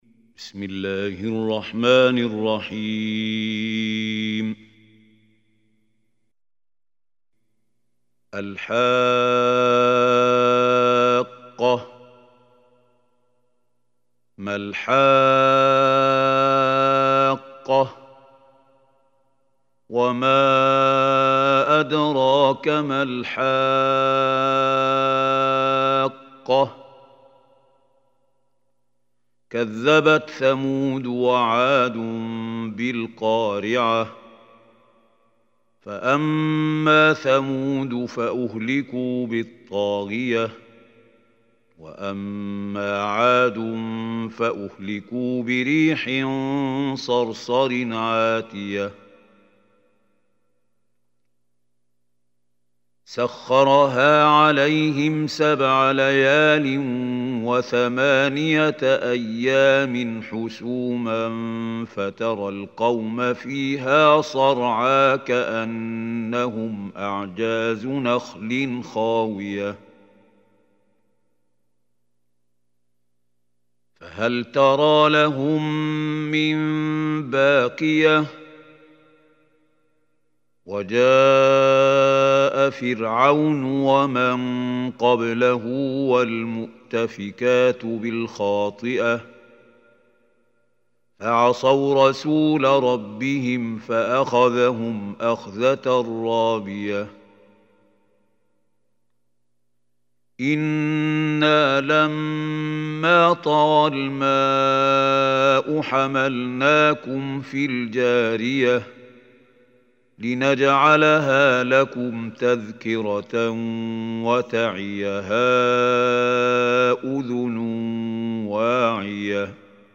Surah Haqqah Recitation by Mahmoud Khalil Hussary
Surah Haqqah, is 69 surah of Holy Quran. Listen or play online mp3 tilawat / recitation in Arabic in the beautiful voice of Sheikh Mahmoud Khalil Hussary.